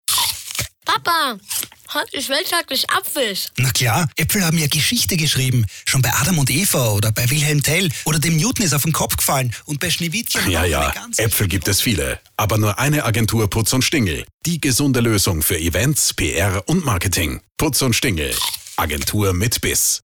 Mödlinger Agentur mit neuem Spot auf 88.6.